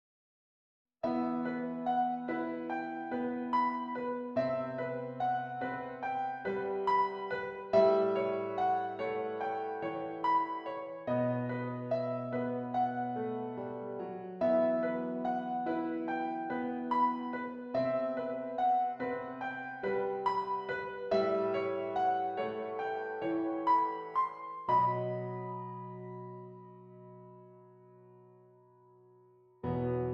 Flute Solo with Piano Accompaniment
Does Not Contain Lyrics
E Minor
Lento